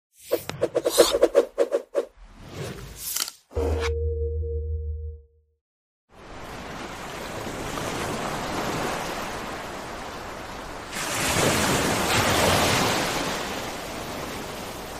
Waves Crashing
Waves Crashing is a free nature sound effect available for download in MP3 format.
079_waves_crashing.mp3